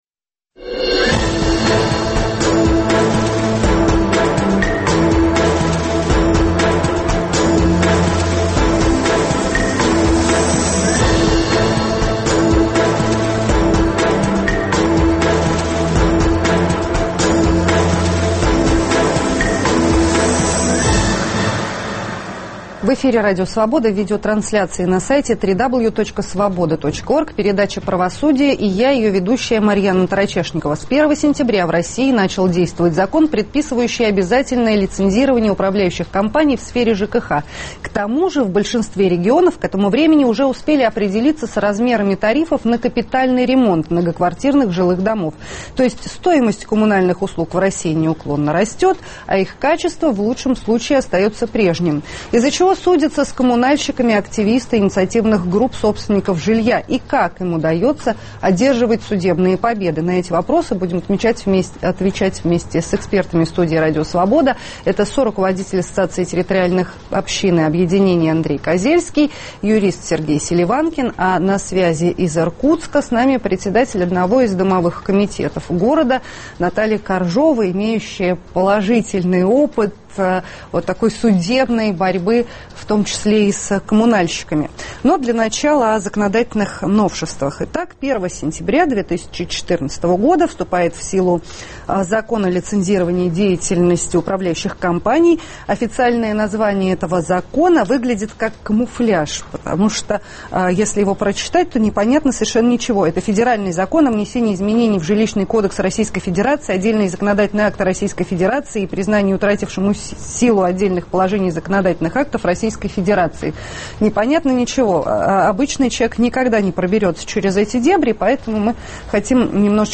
Из-за чего судятся с коммунальщиками активисты инициативных групп собственников жилья и как им удается одерживать судебные победы? В студии Радио Собода